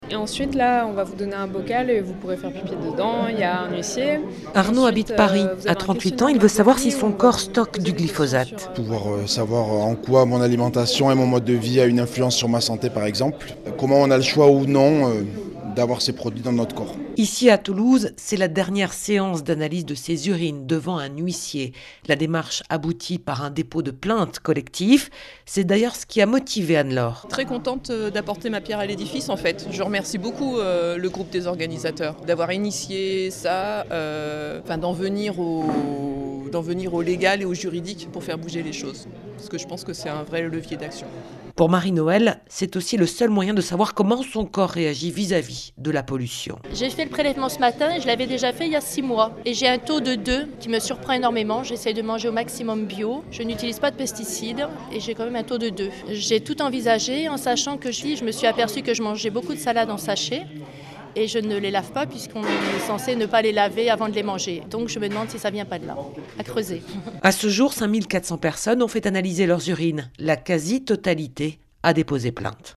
Reportage à Toulouse